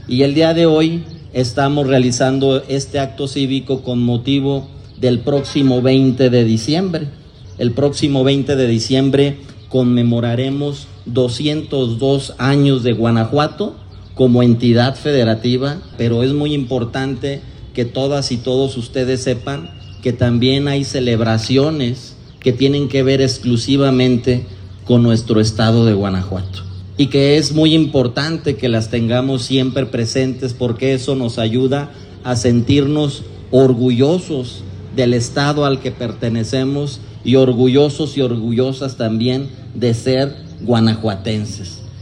AudioBoletines
Irapuato, Gto. 17 de diciembre del 2025 .- Autoridades municipales, en compañía de instituciones educativas, llevaron a cabo un acto cívico para conmemorar el 202 aniversario de la conformación de Guanajuato como Estado Libre y Soberano, así como el Día de la Bandera del Estado de Guanajuato.
Durante el evento, Rodolfo Gómez Cervantes, secretario del Ayuntamiento, recordó este momento histórico en el que el pueblo guanajuatense asumió la responsabilidad de decidir su propio destino, trabajar por su desarrollo y contribuir al crecimiento de la nación.